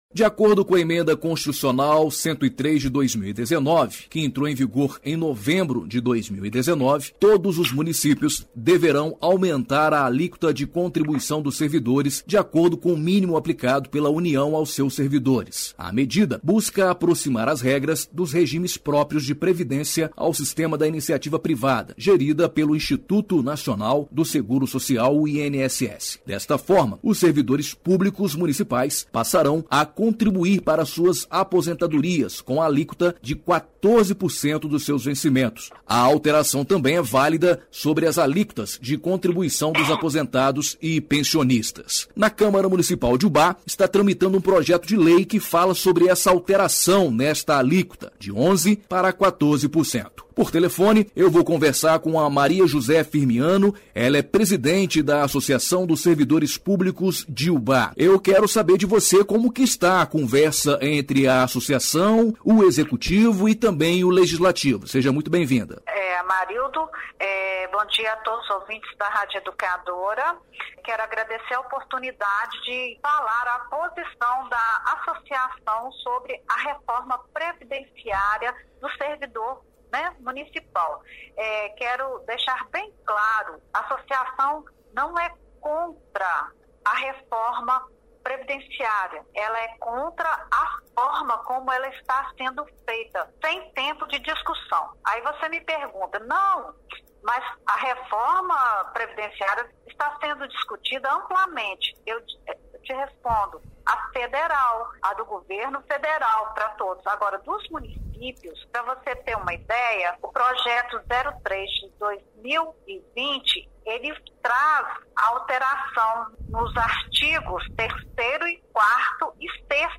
01ENTREVISTA.mp3